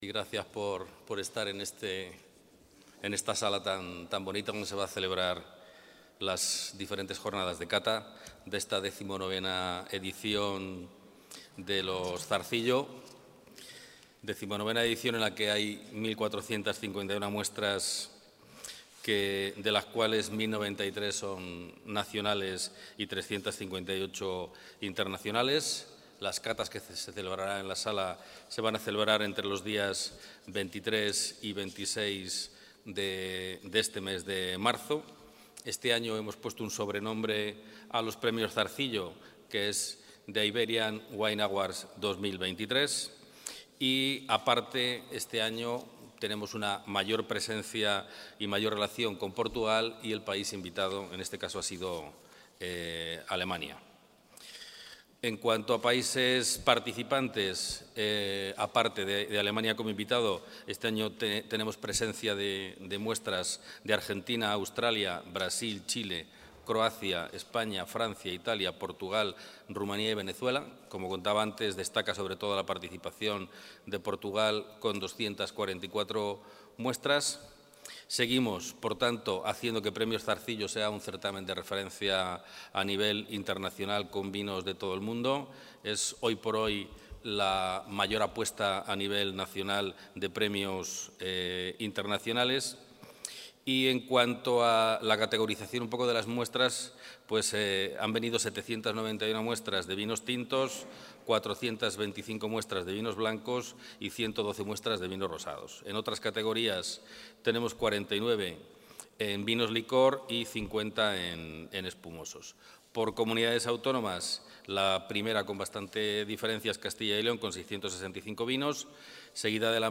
El consejero de Agricultura, Ganadería y Desarrollo Rural, Gerardo Dueñas, ha presentado en rueda de prensa la XIX edición de...
Intervención del consejero.